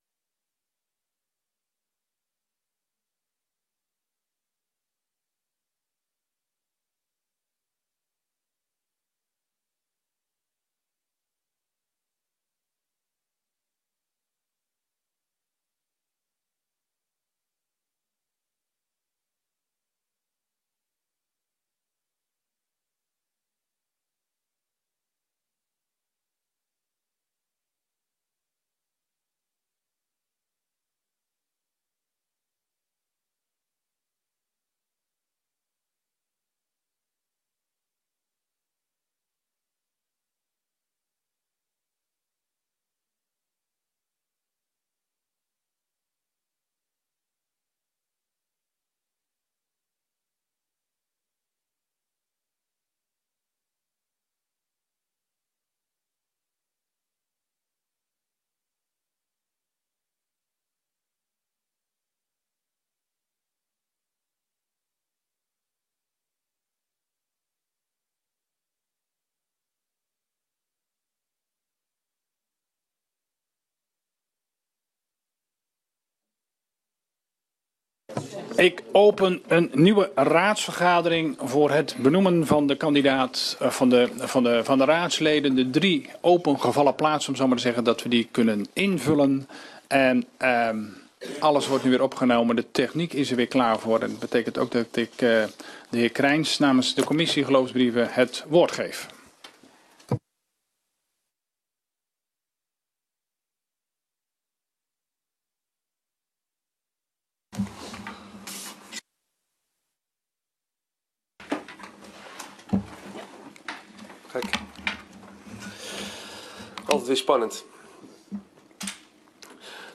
Deze vergadering is aansluitend aan de raadsvergadering van 19.30 uur.
Locatie: Raadzaal